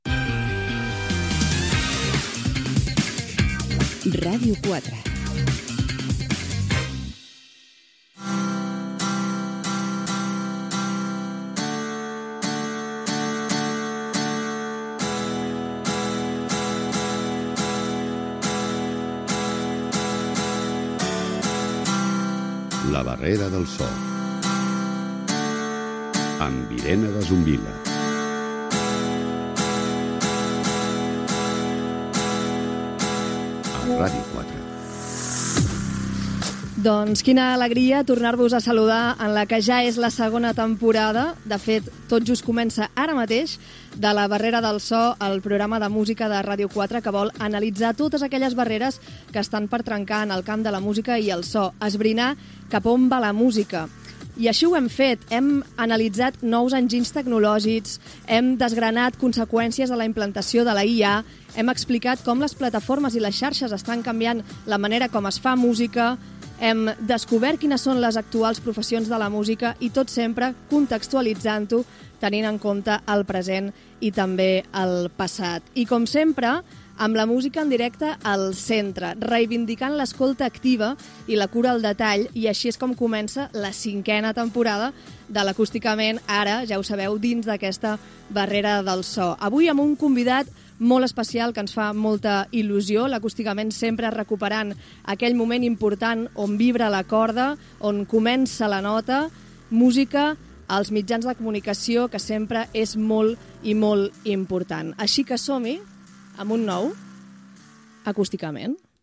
Indicatiu emissora. Careta i inici de la segona temporada del programa.
Musical